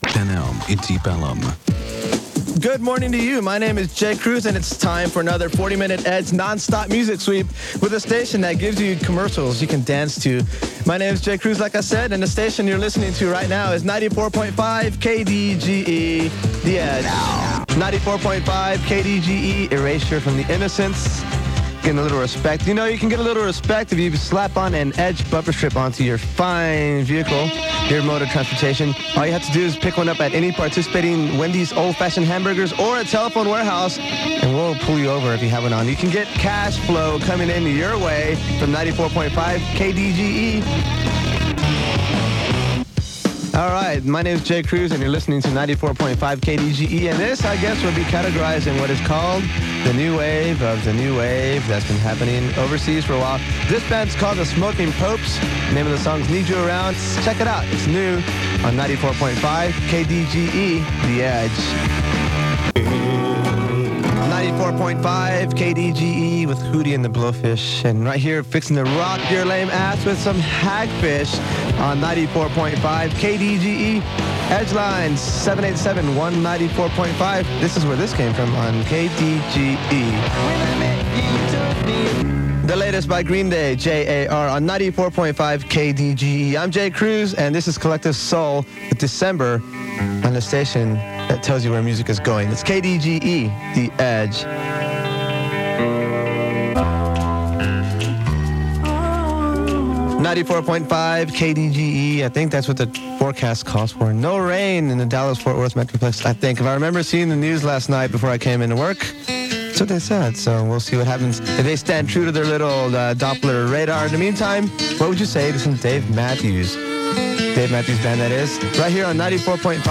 I found this old air check tape while going through a box of memories. J ob seeking DJs would send out to stations near and far as a demo of their broadcasting skills.
KDGE-AIRCHECK-1996.mp3